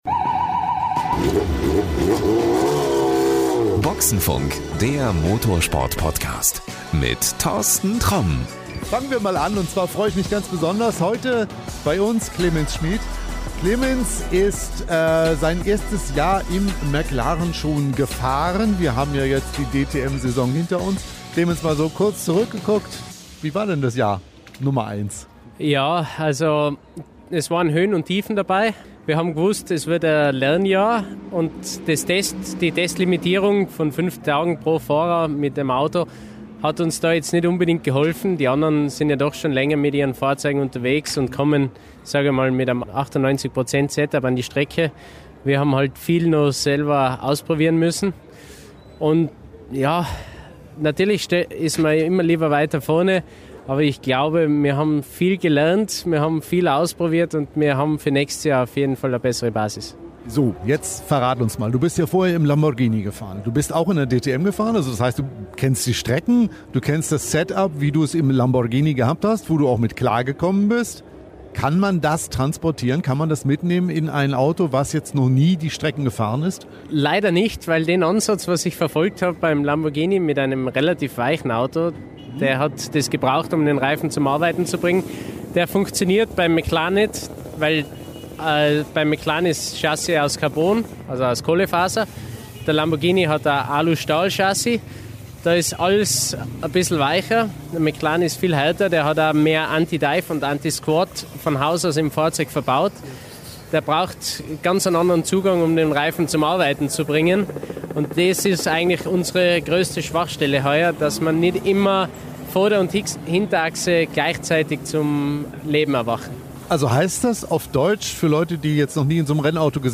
Beim DTM-Finale in Hockenheim